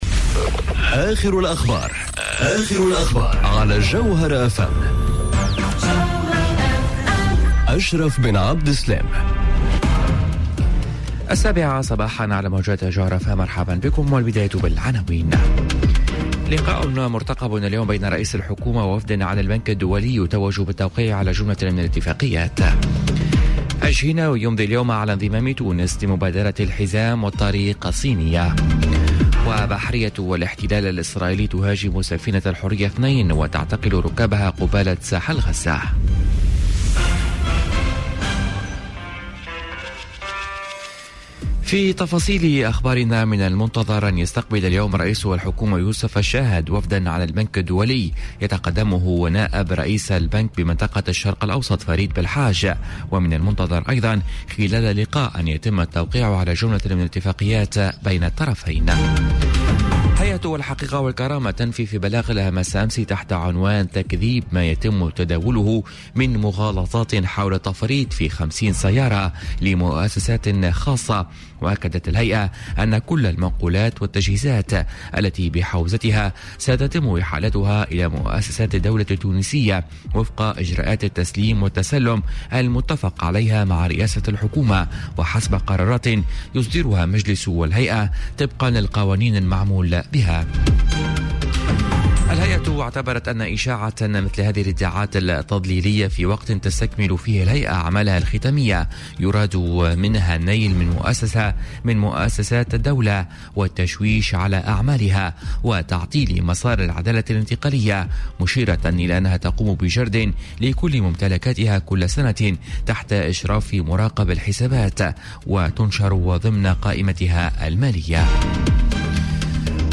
نشرة أخبار السابعة صباحا ليوم الإربعاء 11 جويلية 2018